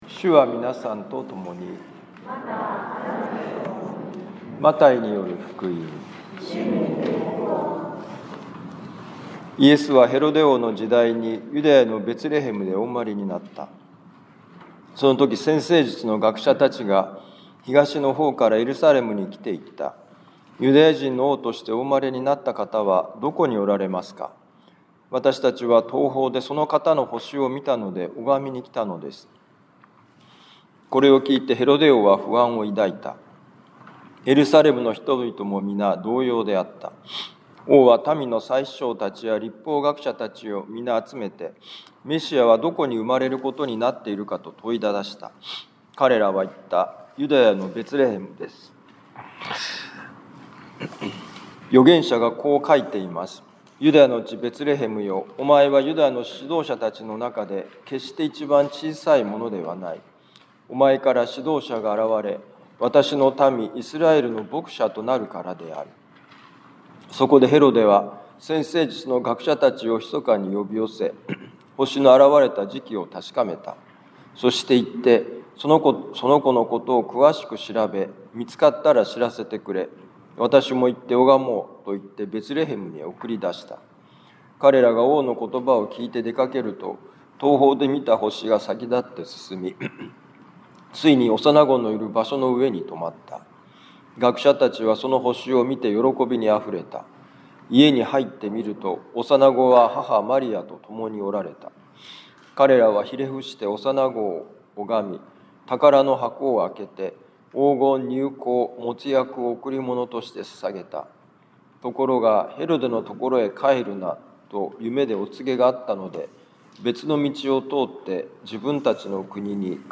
【ミサ説教】
マタイ福音書2章1-12節「夜に星を見、昼に歩く」2026年1月４日主の公現のミサ 防府カトリック教会